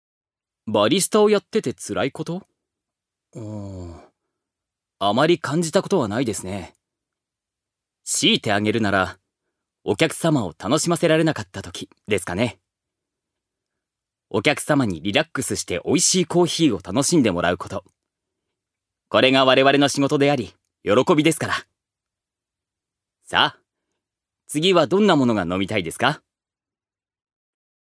預かり：男性
セリフ２